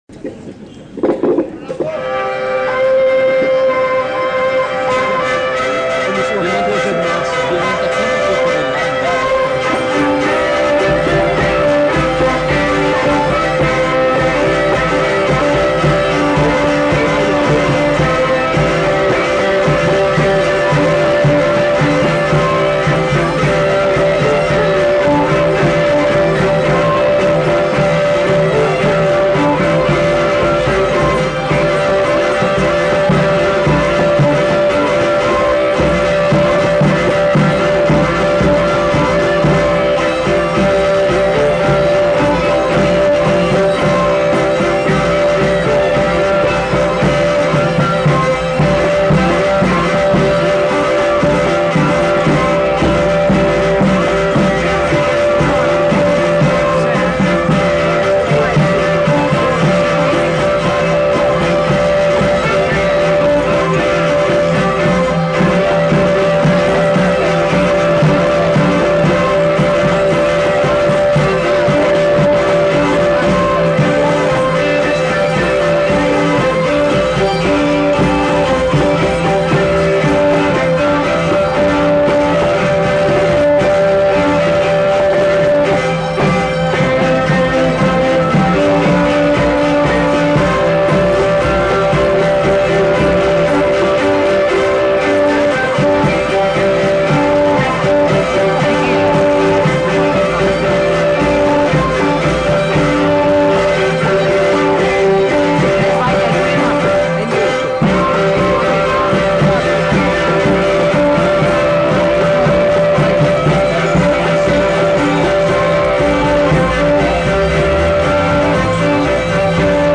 Gigue
Aire culturelle : Pays d'Oc
Lieu : Pinerolo
Genre : morceau instrumental
Instrument de musique : violon ; vielle à roue ; percussions
Danse : gigue